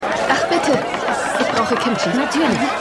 Pachinko_2x06_KoreanischeKundin.mp3